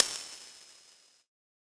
UHH_ElectroHatC_Hit-11.wav